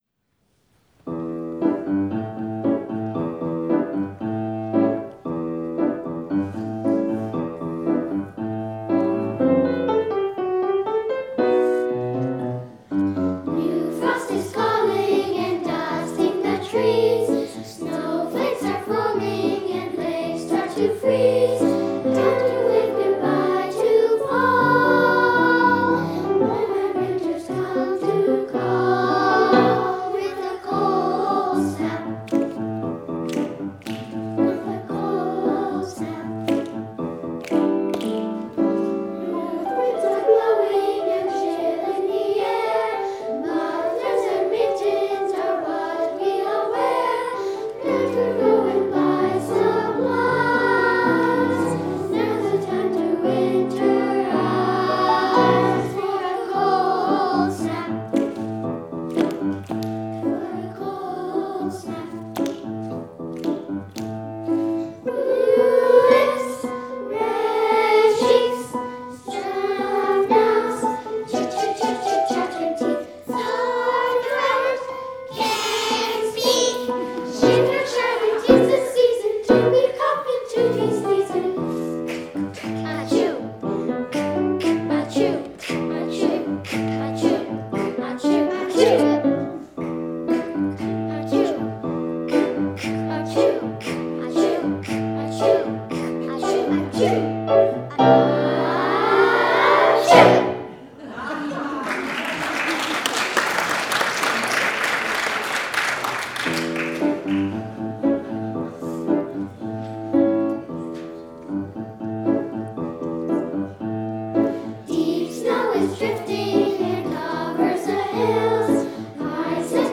Recording Location: James Bay United Church, Victoria BC
Status: Raw, unedited
The 20-member children's chorus
128kbps Stereo